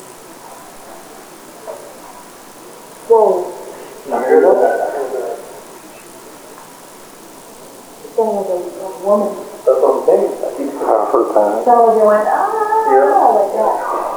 Cains-Ballroom-EVP-Woman-1.wav